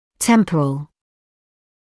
[‘tempərəl] [‘тэмпэрэл] височный; временный